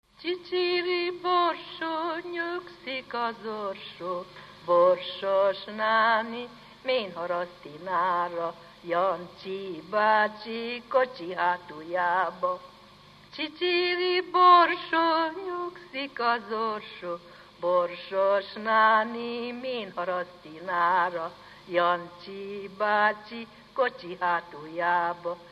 Dunántúl - Baranya vm. - Mohács
ének
Stílus: 6. Duda-kanász mulattató stílus
Szótagszám: 5.5.X.6